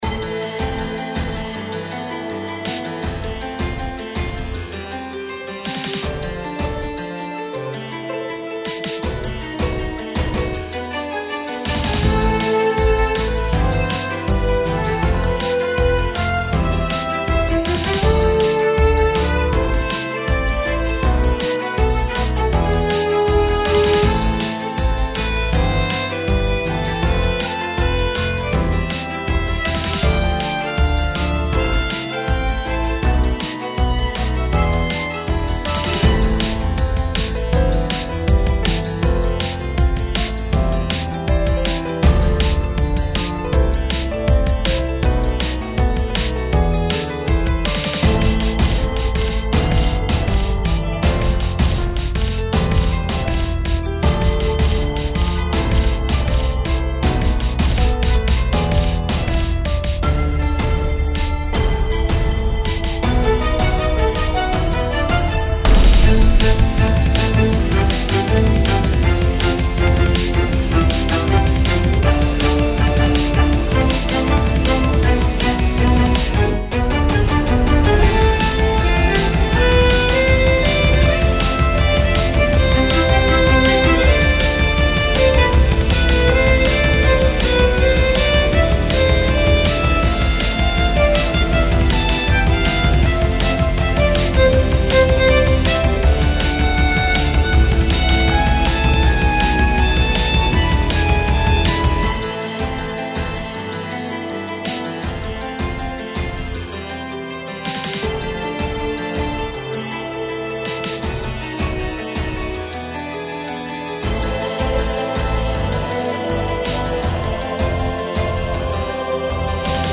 「光と闇の対比」をテーマに制作したダーク・クラシカル系のインスト。
• テンポ：ゆったりとしたBPM（静と動の落差を際立たせる）
• サウンド設計：中域を中心に広がるリバーブ感で、空間演出を重視しています
クライマックス部分では低音弦のうねりとベルの逆再生的な響きで幻想感を強調しています。